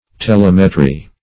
Telemetry \Te*lem"e*try\, n.